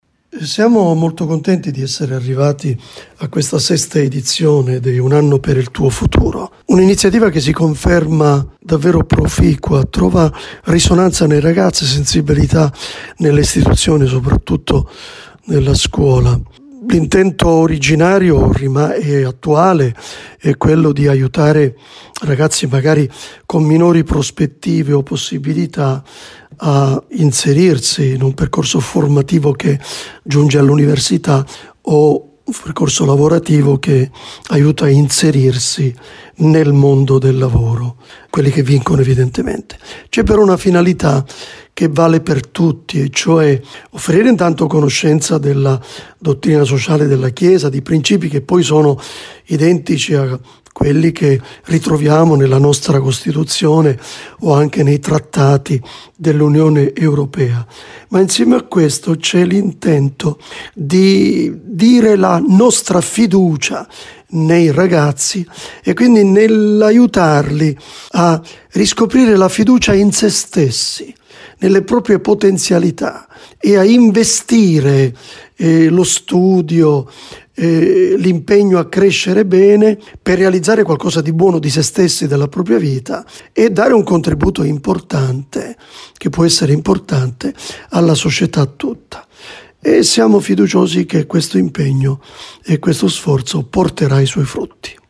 «Un’iniziativa che si conferma davvero proficua e che trova risonanza nei ragazzi e sensibilità nelle istituzioni, soprattutto nella scuola…» ha spiegato con particolare soddisfazione lo stesso vescovo Mariano Crociata nell’intervista ascoltabile di seguito:
vescovo-borse-di-studio.mp3